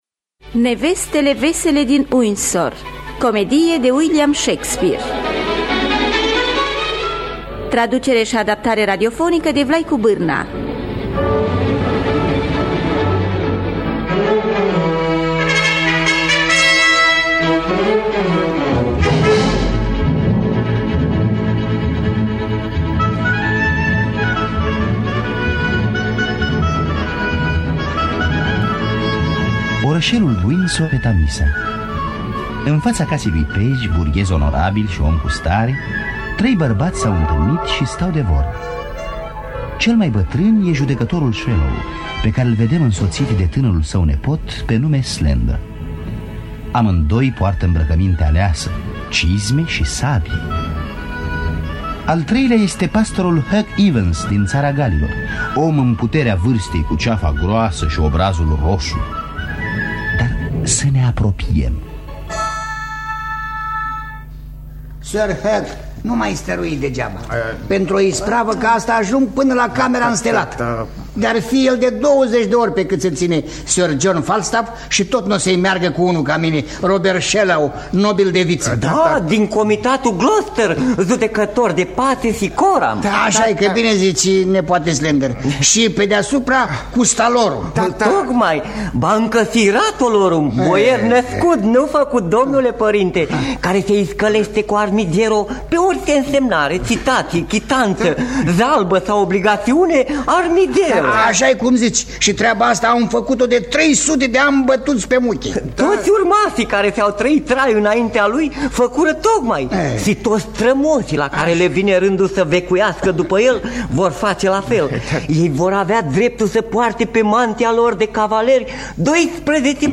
Traducerea şi adaptarea radiofonică de Vlaicu Bîrna.